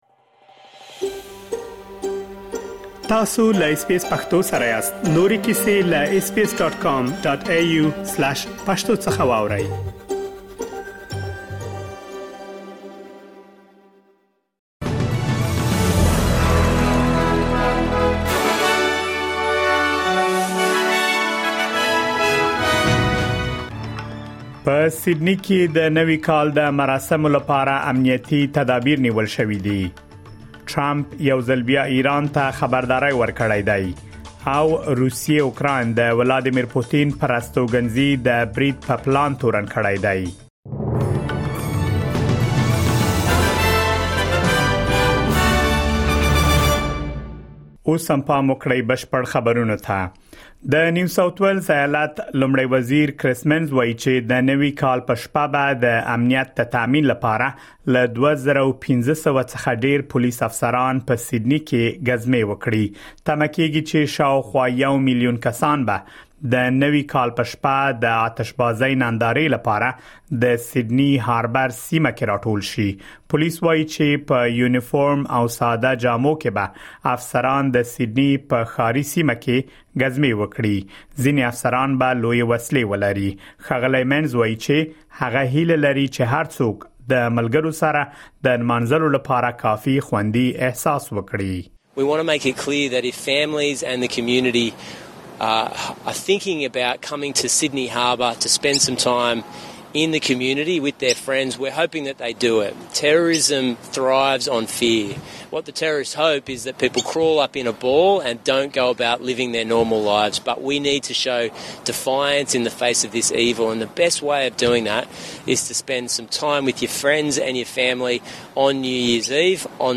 د اسټراليا او نړۍ مهم خبرونه
د اس بي اس پښتو د نن ورځې لنډ خبرونه دلته واورئ.